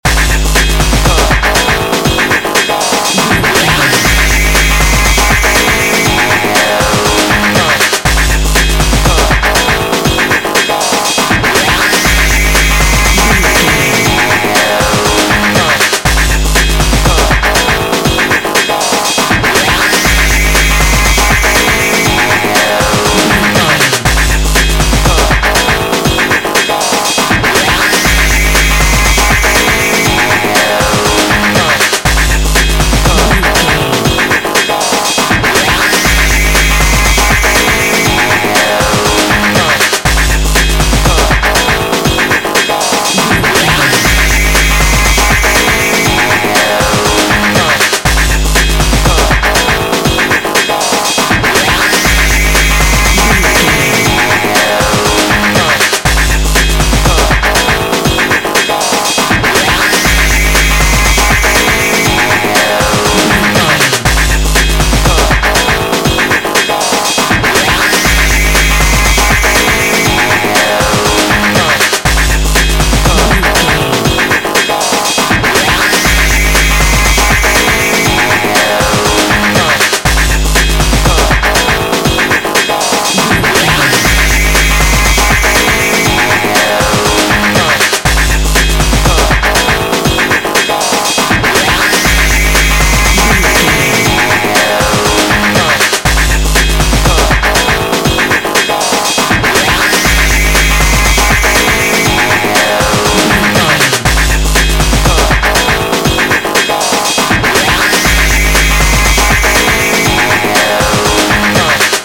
Aeolian/Minor
G#
energetic
powerful
heavy
futuristic
hypnotic
industrial
drum machine
synthesiser
vocal
techno
electro house
synth lead
synth bass